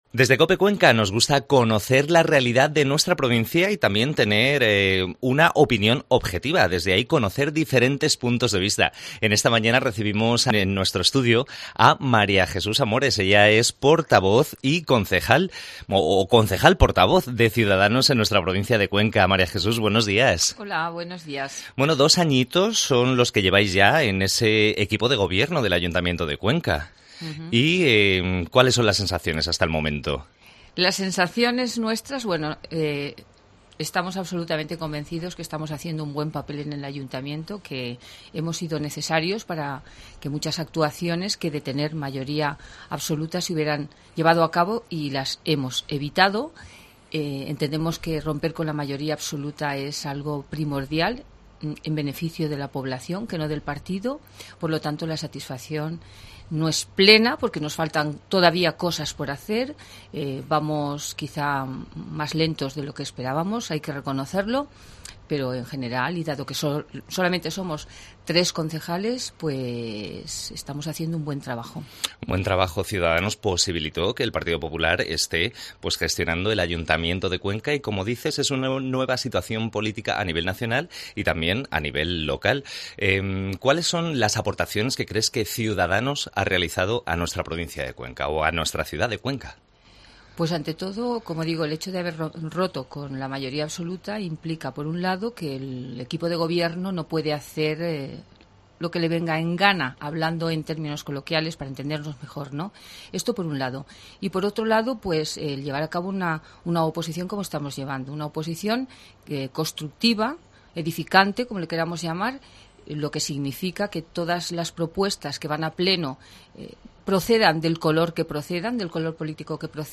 AUDIO: Entrevista a Maria Jesús Amores